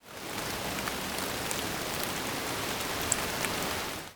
PixelPerfectionCE/assets/minecraft/sounds/ambient/weather/rain3.ogg at 937abec7bb071e95d485eece1172b7fc80203bcf
rain3.ogg